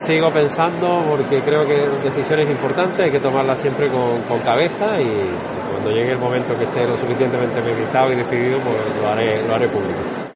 El presidente de la Junta de Andalucía, Juanma Moreno, hoy en la Semana Santa de Granada